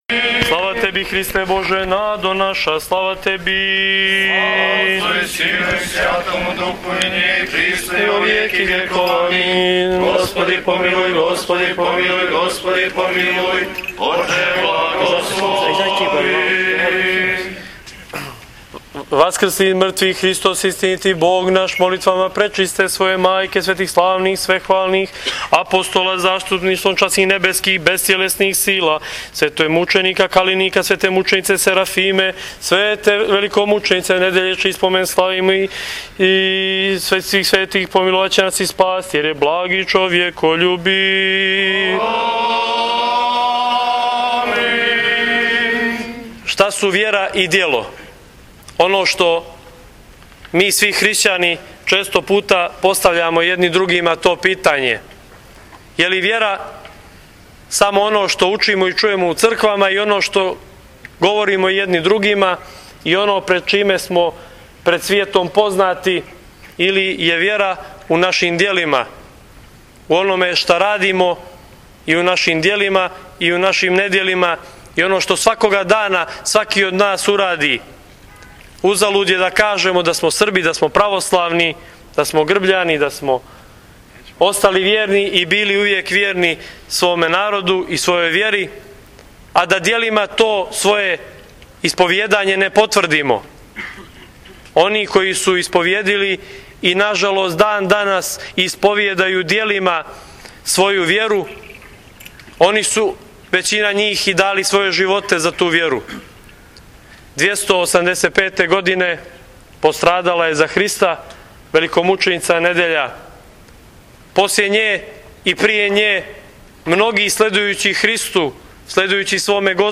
Пелиново Прослава Св.Недјеље 2014.
Бесједу